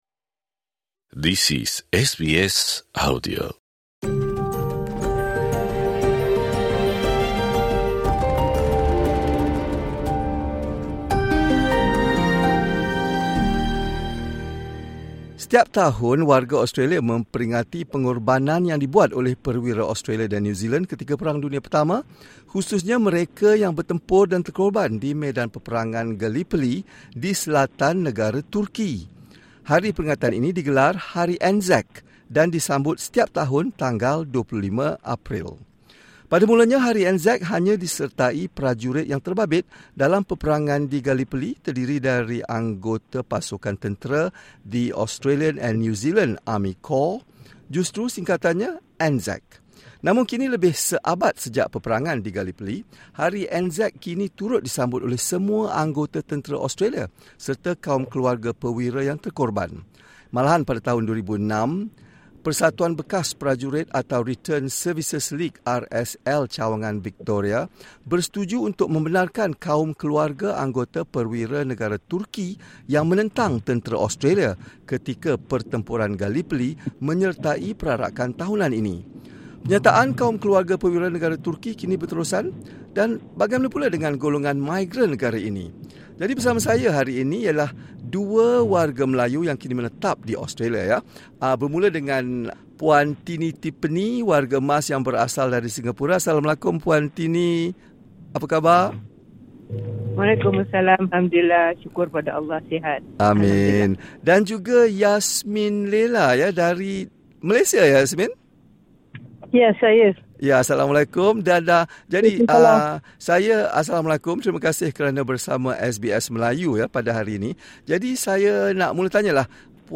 Hari peringatan ini digelar Hari Anzac. SBS Bahasa Melayu berbual dengan dua warga Melayu untuk mendapatkan pandangan mereka tentang hakikat hari perwira ini.